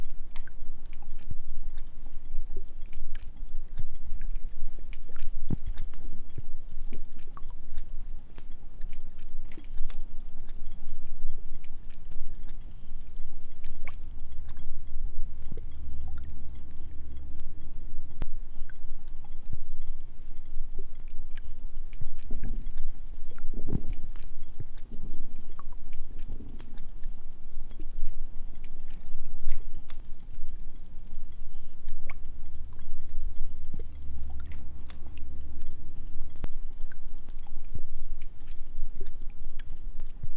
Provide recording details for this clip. Copy of WAV file Within this course, I learned how to create Ambisonic media. I attempted to create a Sound Garden from collected sounds and position them in an Ambisonic manner.